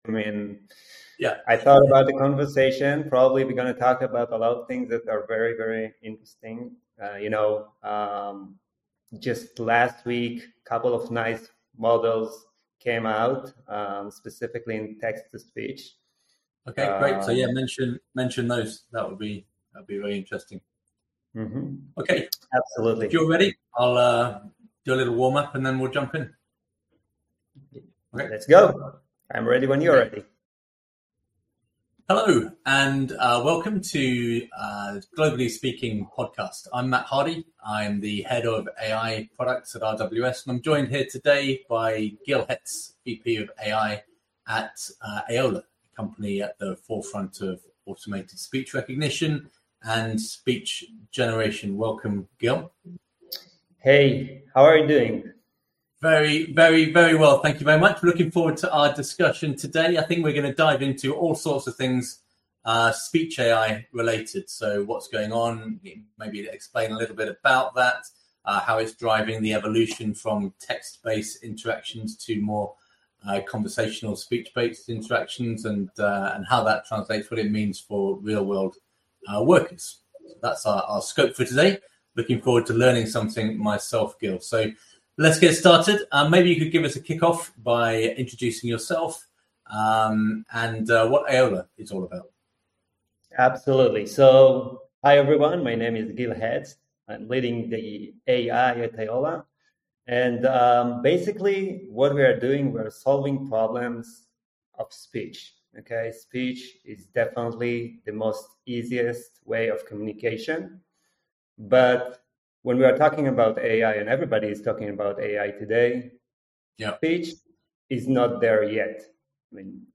Hear their perspectives on how women and gender-diverse individuals can shape the future of AI, advocating for equitable representation, and ensuring technology serves diverse global communities. Gain insights into navigating gender biases, fostering inclusivity, and the importance of curiosity and mentorship. Join us for an inspiring conversation on resilience, leadership, and the power of representation.